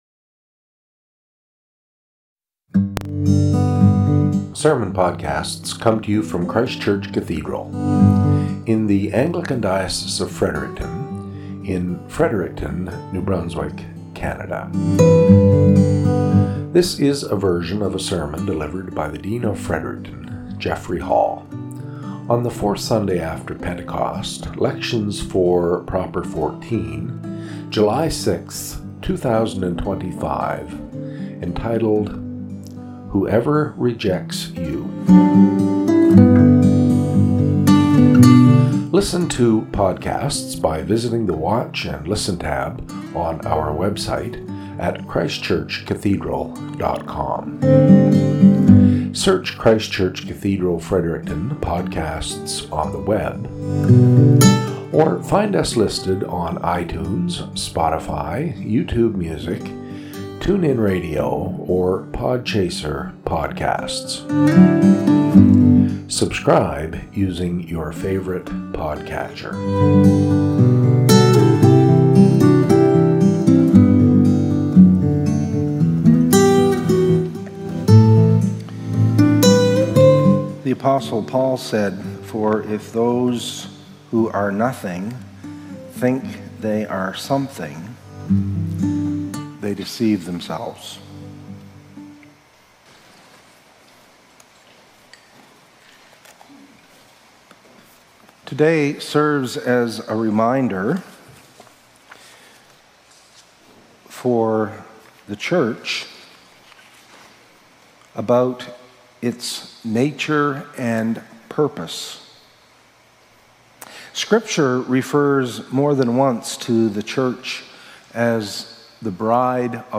SERMON - "Whoever Rejects You"